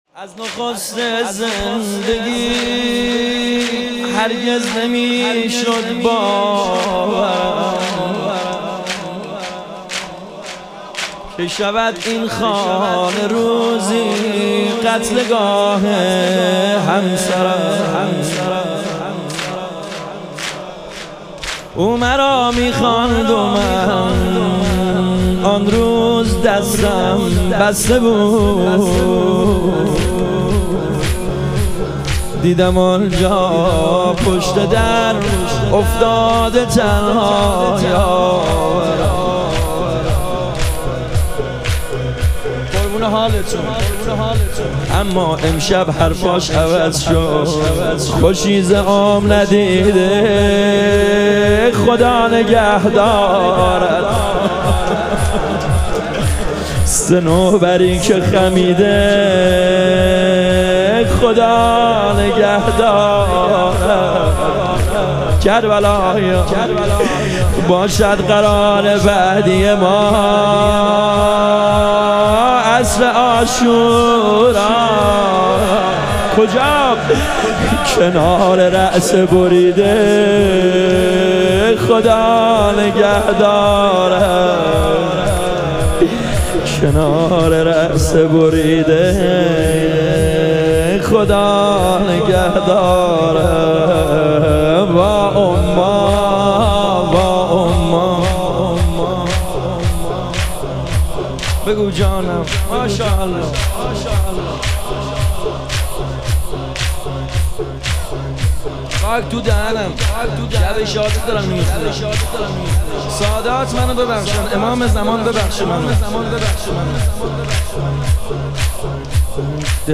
ایام فاطمیه اول - تک